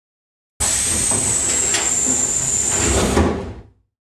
Двери пассажирского автобуса